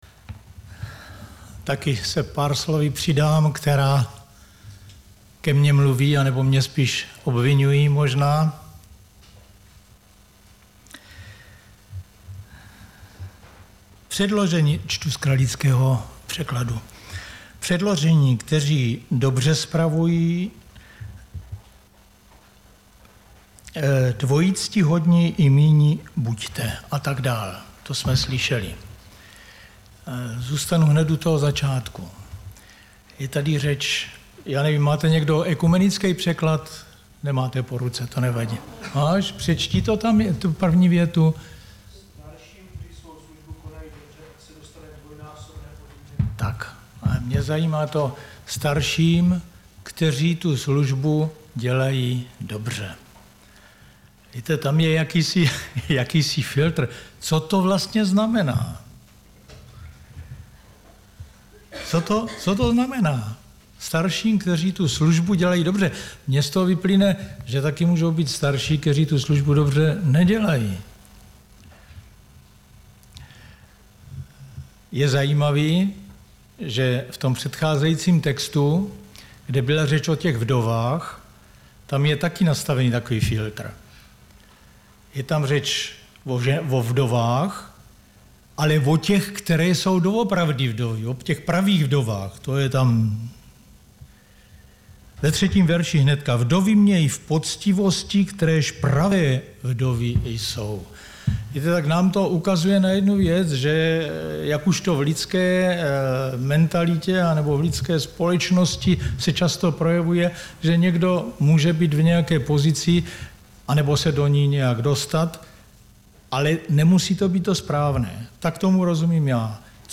Středeční vyučování
Záznamy z bohoslužeb